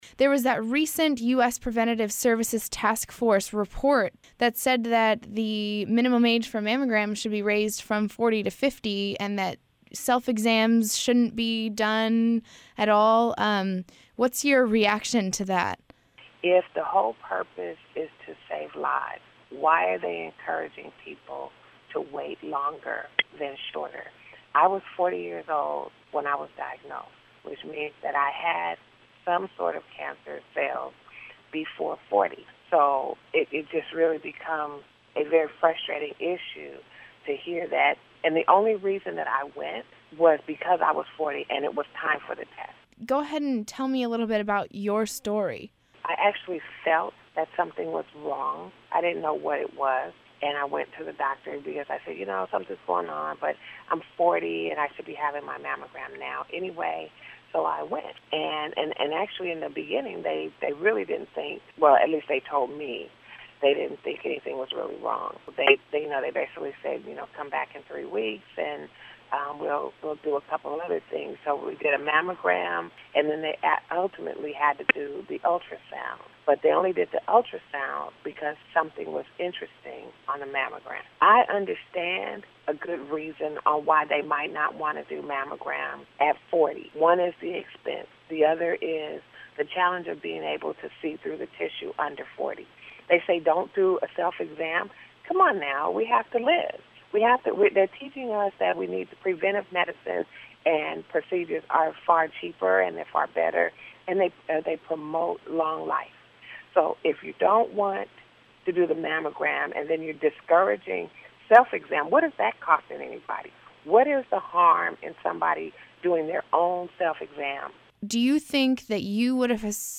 cancerhostinterview.mp3